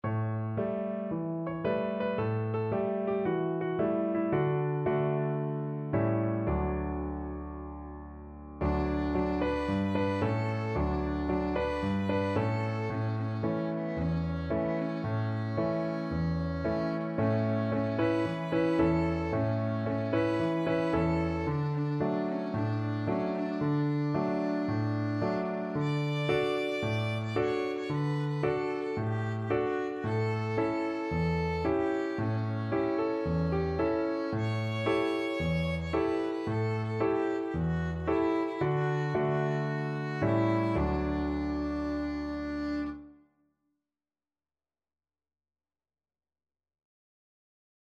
World Austrilasia New Zealand
4/4 (View more 4/4 Music)
Cheerfully! =c.112
Traditional (View more Traditional Violin Music)